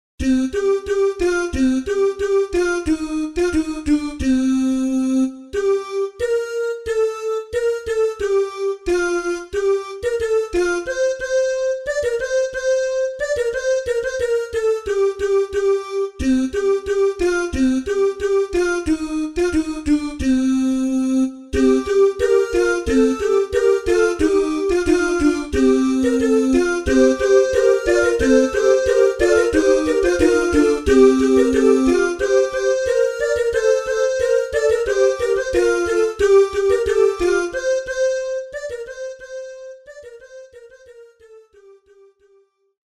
RÉPERTOIRE  ENFANTS
CANONS